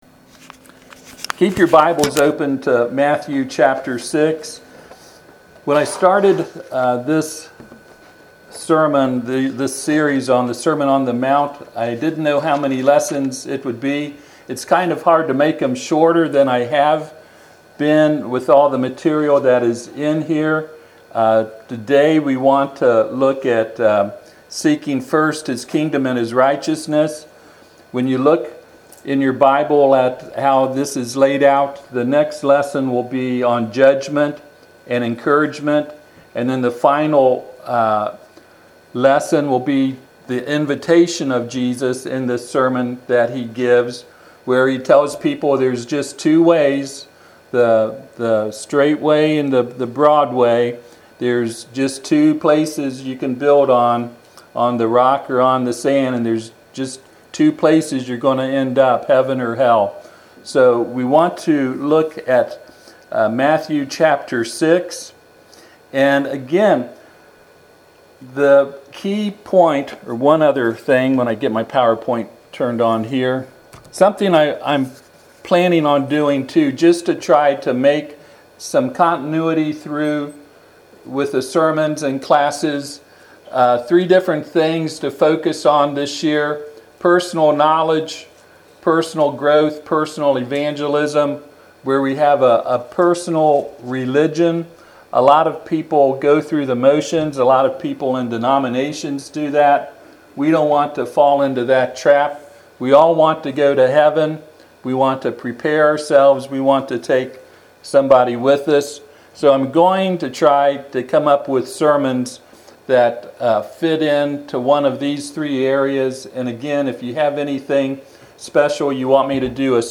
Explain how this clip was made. Passage: Matthew 6:19-34 Service Type: Sunday AM